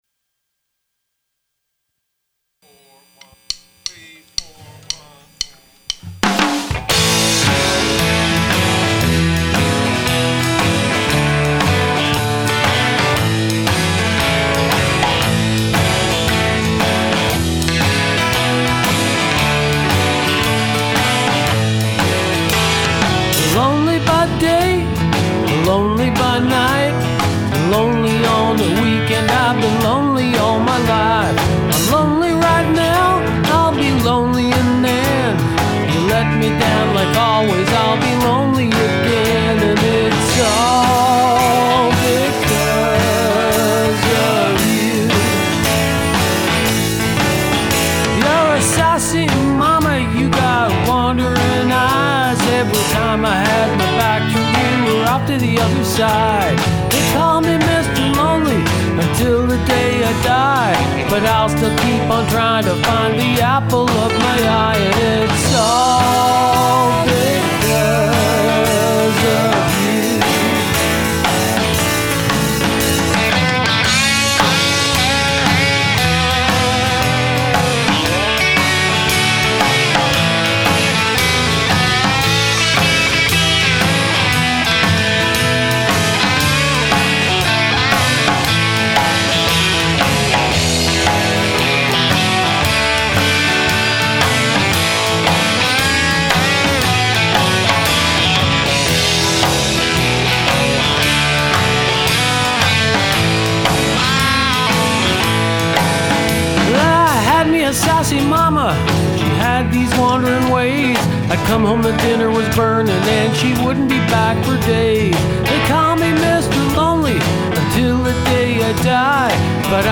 "angry but optimistic" as it has been put to me.